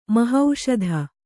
♪ mahauṣadha